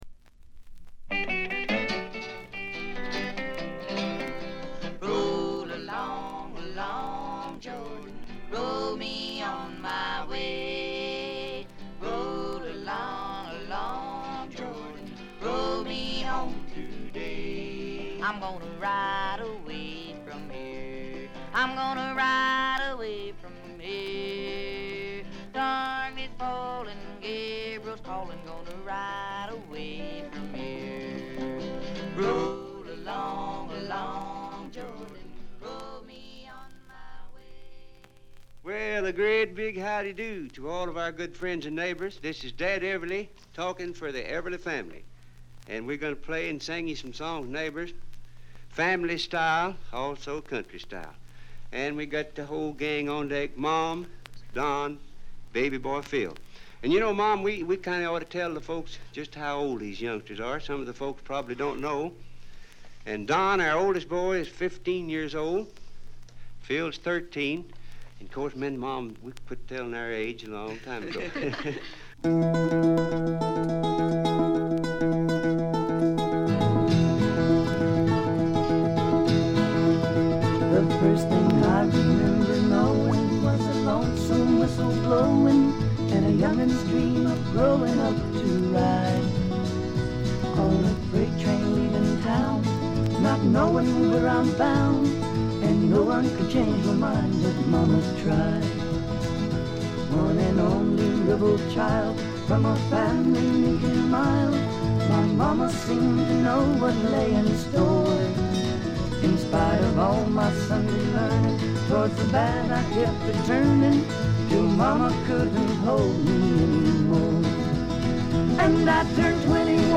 ところどころでチリプチ。目立つノイズはありません。
試聴曲は現品からの取り込み音源です。